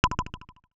Simple Cute Alert 12.wav